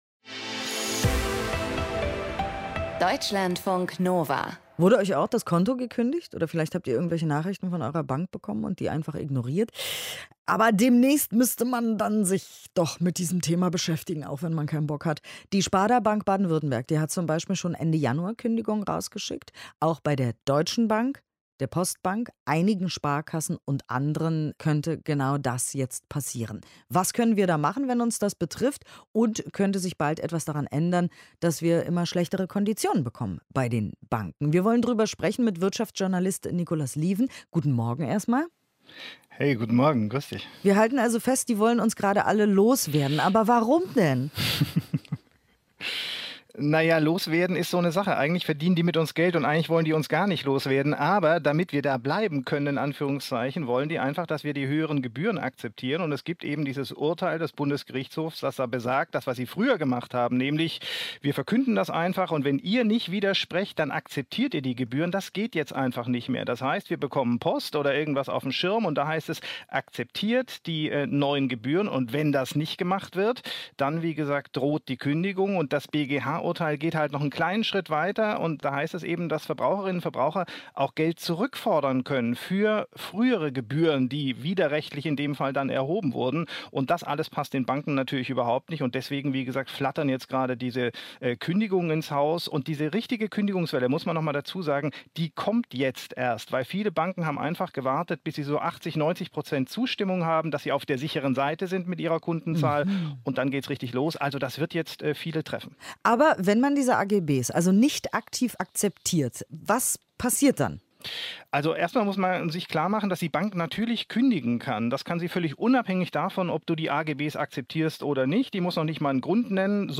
Literarische Features und Diskussionen.